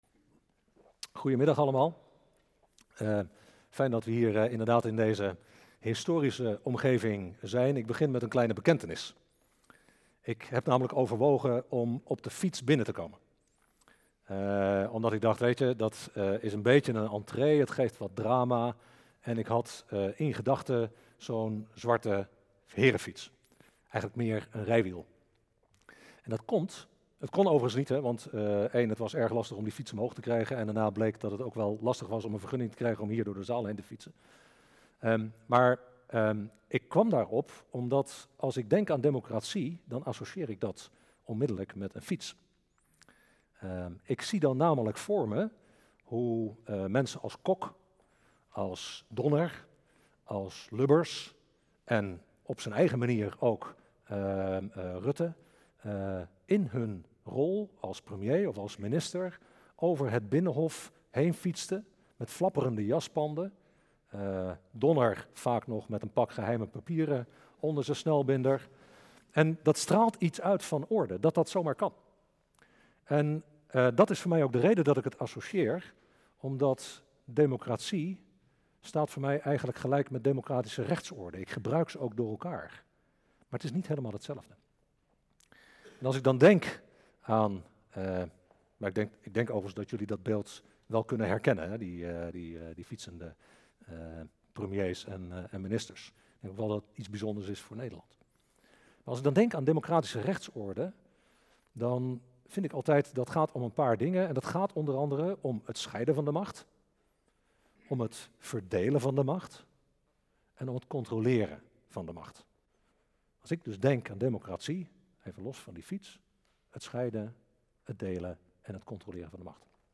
Toespraak van de directeur-generaal van de AIVD Rob Bertholee op de bijeenkomst van 7 september 2017 'In dienst van de democratie' over het belang van de democratie en de rol van de AIVD erin. Femke Halsema en Marc Chavannes reageren op zijn verhaal vanuit politiek en journalistiek.